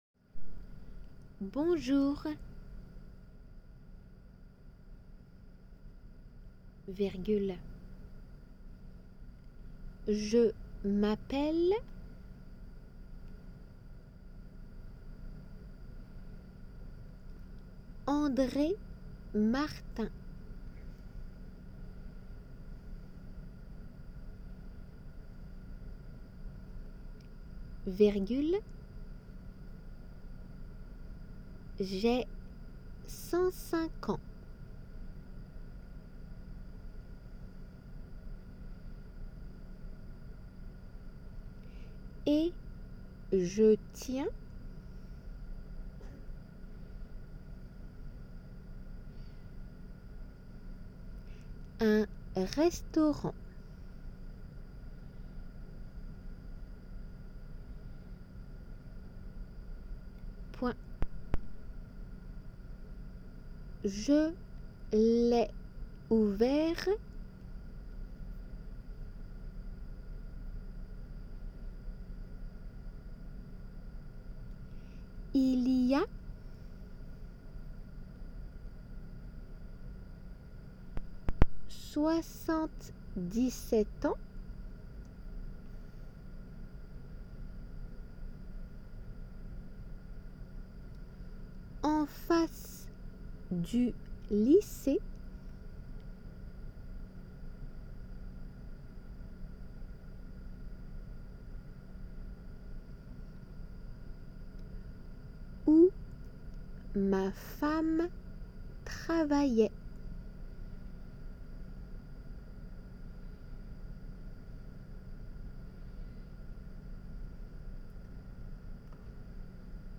春季仏検2級―聞き取り―音声－デイクテ
デイクテの速さで